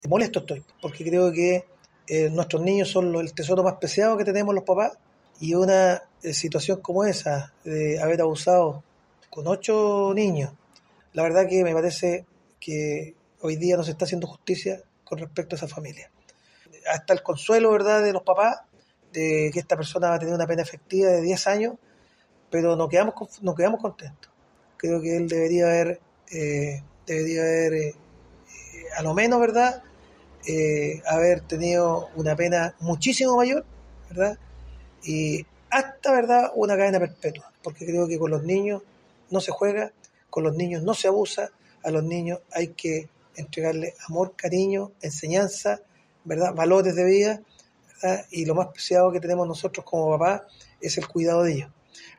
comunal-de-villa-alemana-comunal-de-quilpue-juicio-abreviado-palabras-alcalde.mp3